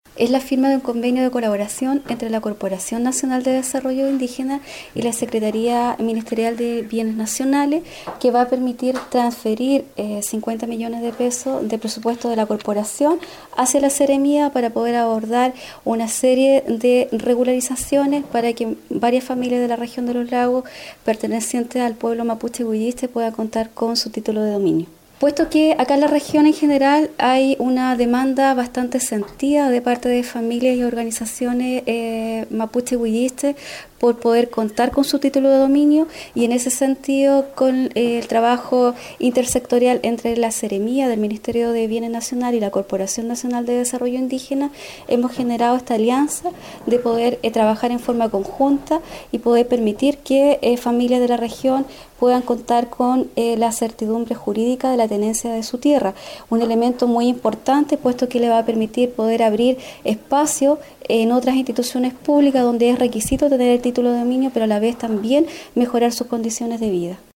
Habla Directora Regional de CONADI Claudia Pailalef